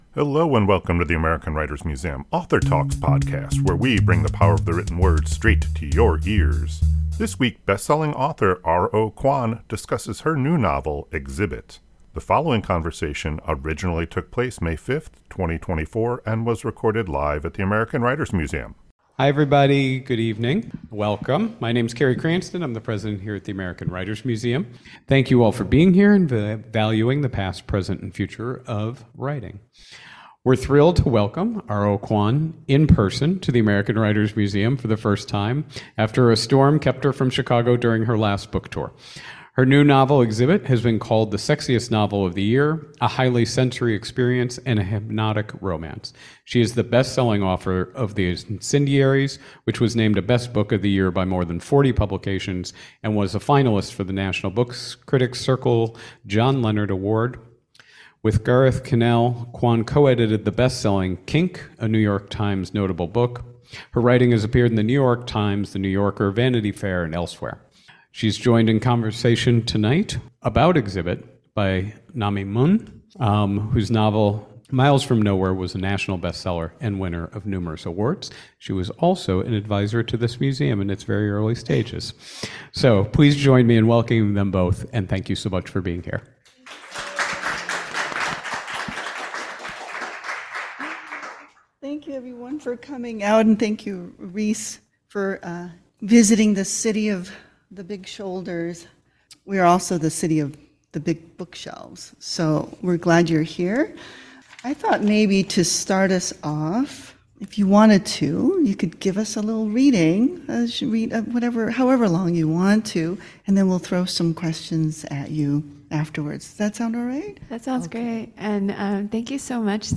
This conversation originally took place May 5, 2024 and was recorded live at the American Writers Museum.